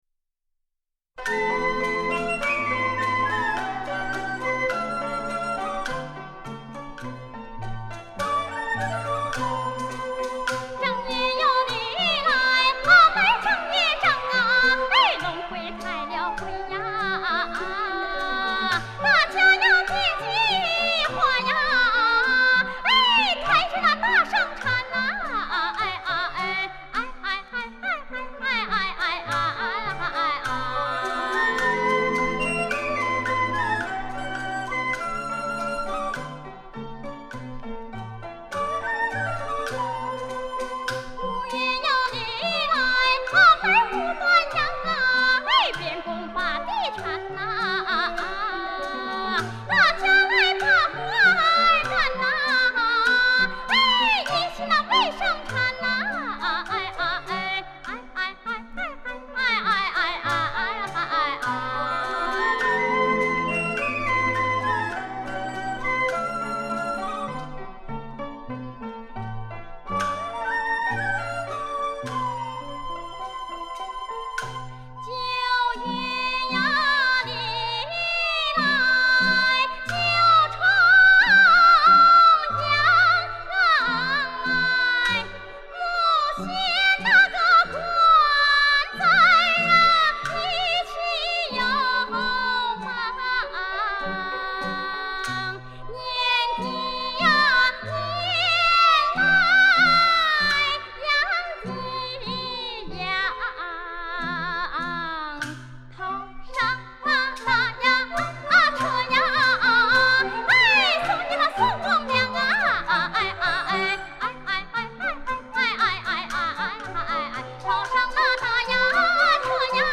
东北民歌